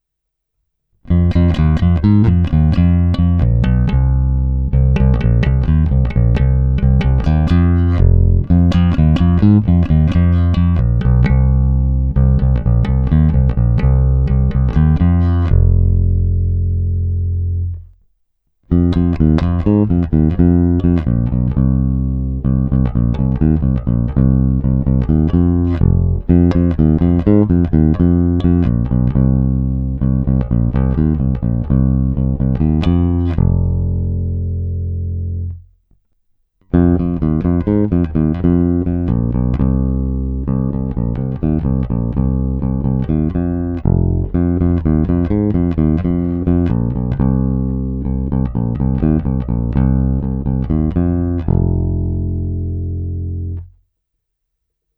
Není-li uvedeno jinak, následující nahrávky jsou provedeny rovnou do zvukové karty, jen normalizovány, jinak ponechány bez úprav. Tónová clona vždy plně otevřená, a všechno jen v pasívním režimu.
Hra mezi snímačem a kobylkou